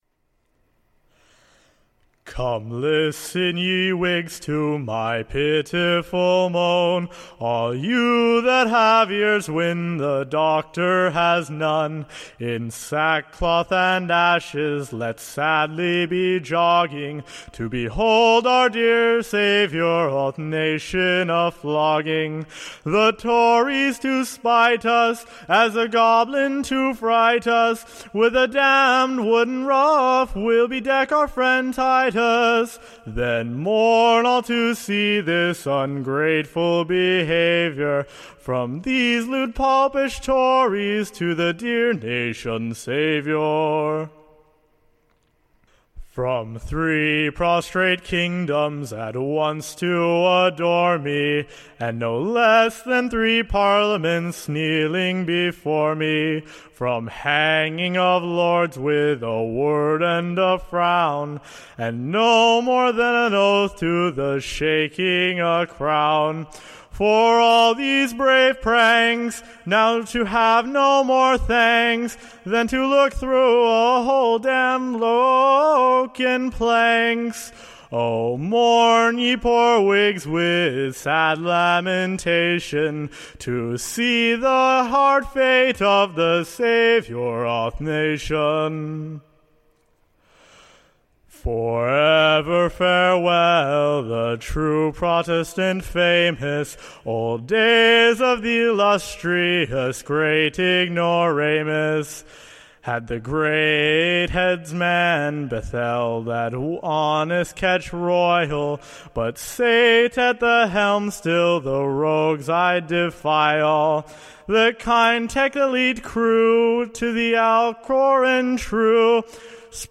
Recording Information Ballad Title THE / Salamanca Doctor's Farewe[l] / OR, / TITUS's / Exaltation to the Pillory, upon his Conviction of PERJURY. / A BALLAD.